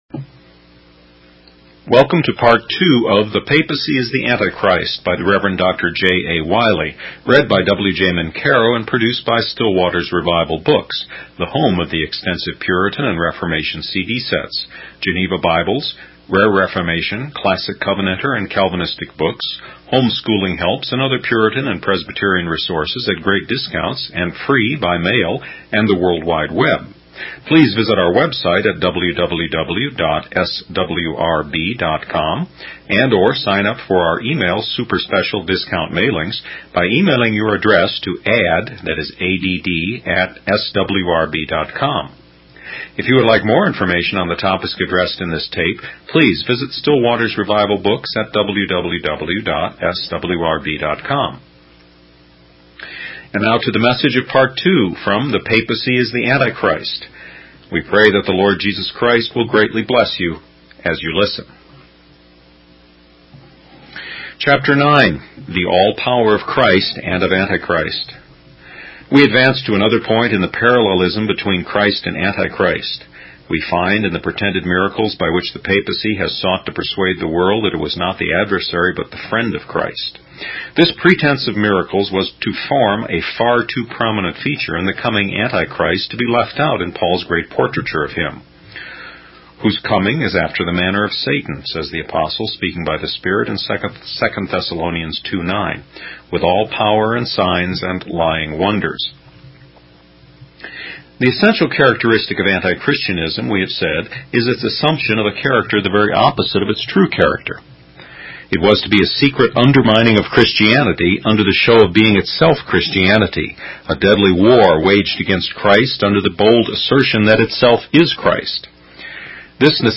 In this sermon, the speaker reflects on the chaotic and tumultuous events that took place from the fifth to the fifteenth century.